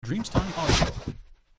Klappen-Bewegung
• SFX